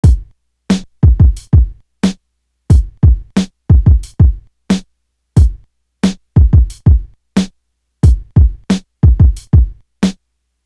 Definition Drum.wav